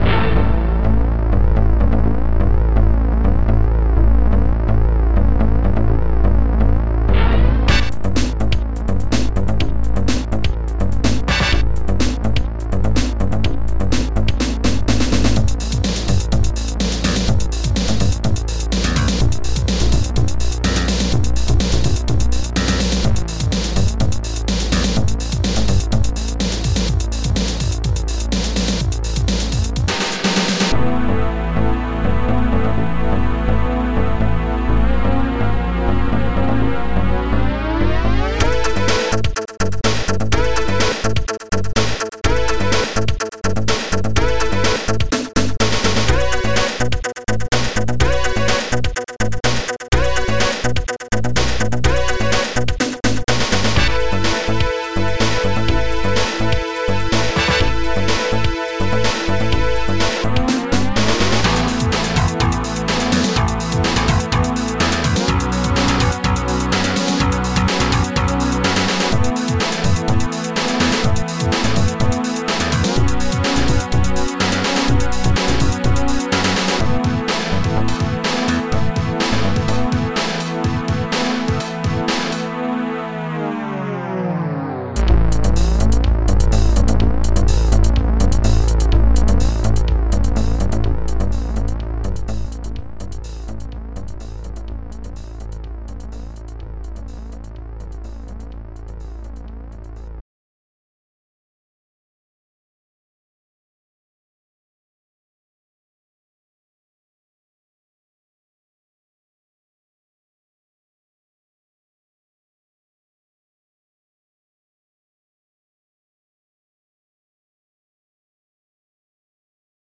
SThororstring
dxbass
orch.hiting
ST-50:SP12SNARE
ST-50:HIGHHAT6
sawsynth_arrested2
ELGUITAR1